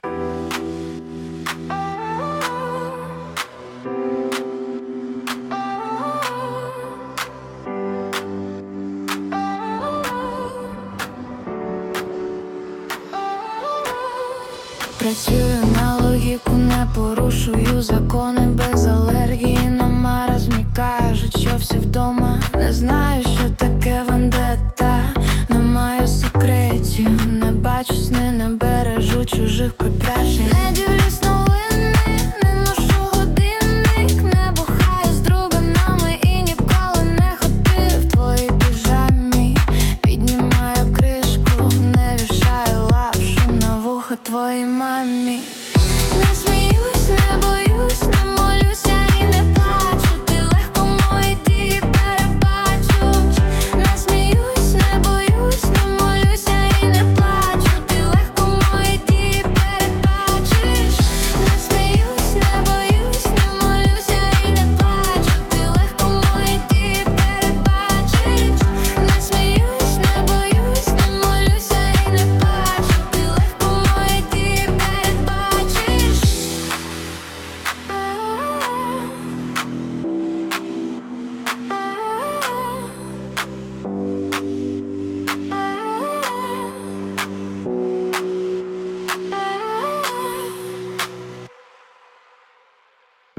жіночий голос явно не підходить до даного тексту hi